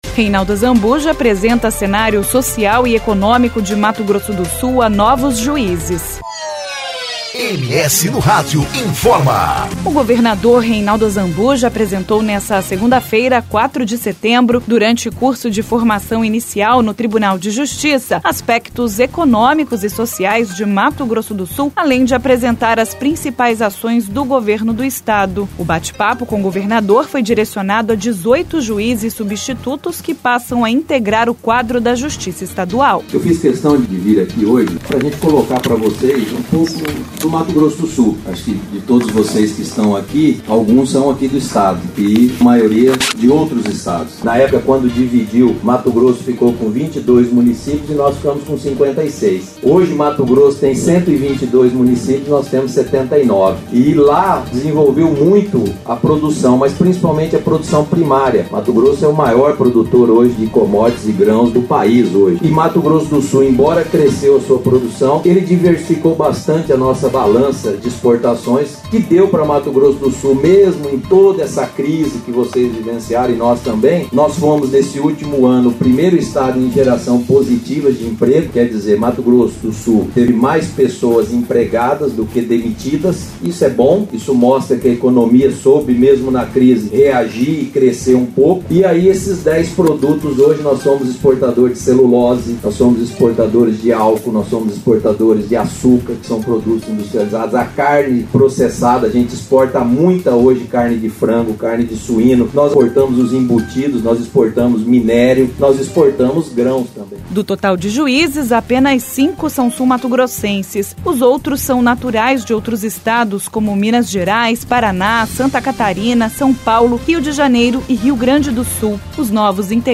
O governador Reinaldo Azambuja apresentou nessa segunda-feira, 4 de setembro, durante Curso de Formação Inicial, no Tribunal de Justiça, aspectos econômicos e sociais de Mato Grosso do Sul, além de apresentar as principais ações do Governo do Estado.
O bate-papo com o governador foi direcionado a dezoito juízes substitutos que passam a integrar o quadro da Justiça estadual.